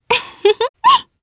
Selene-Vox-Laugh_jp.wav